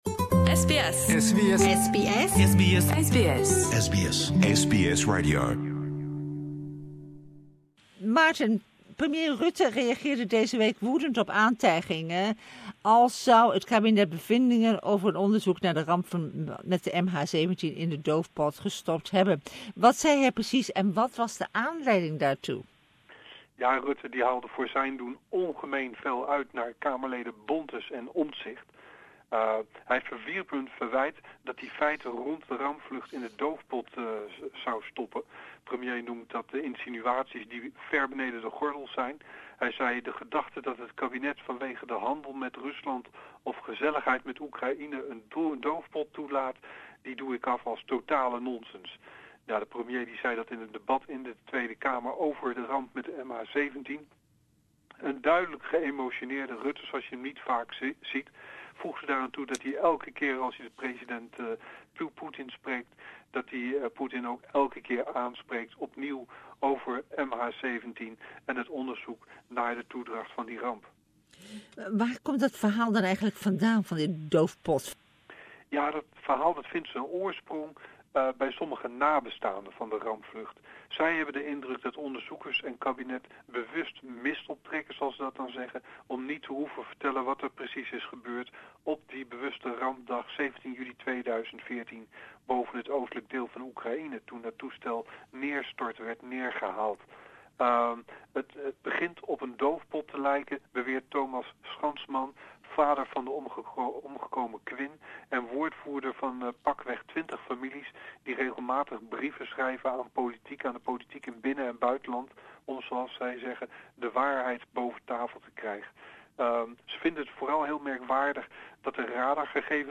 Why was Premier Rutte so upset about questions concerning the MH17 crash? Dutch correspondent